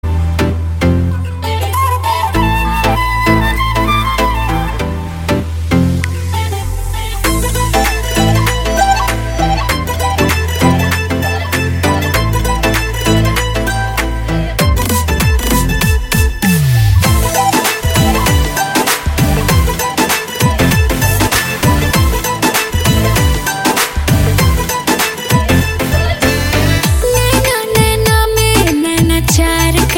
BHOJPURI SONG